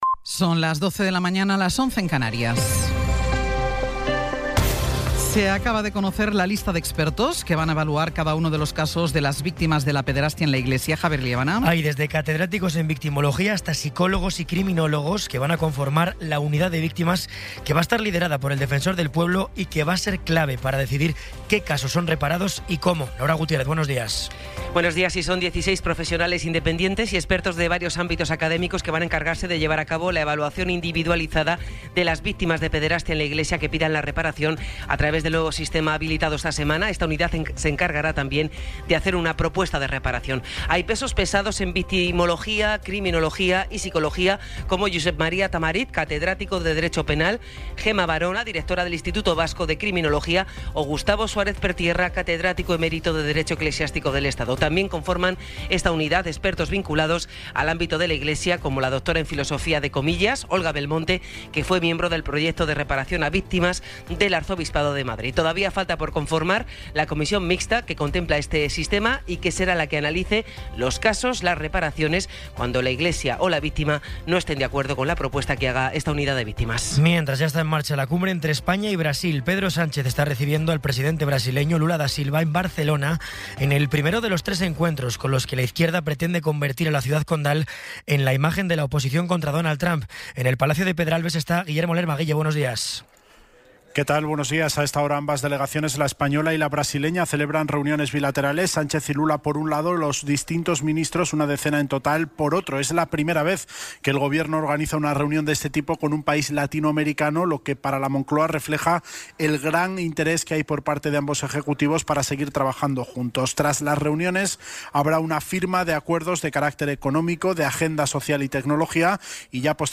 Resumen informativo con las noticias más destacadas del 17 de abril de 2026 a las doce.